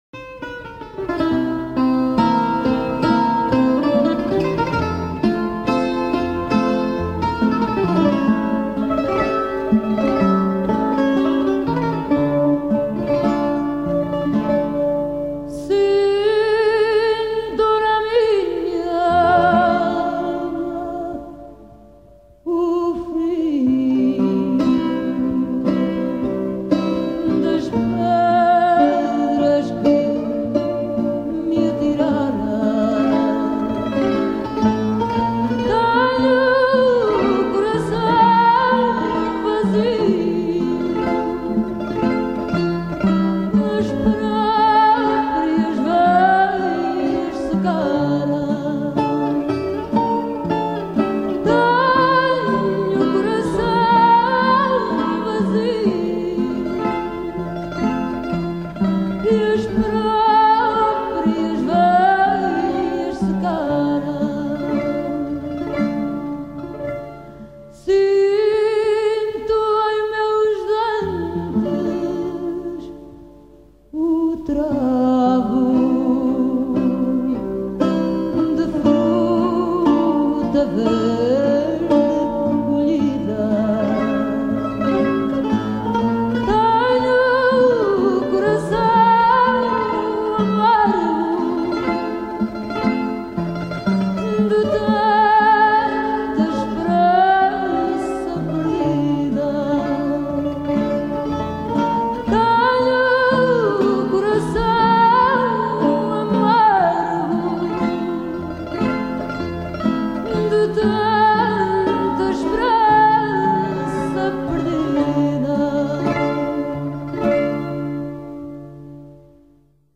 chant
guitare portugaise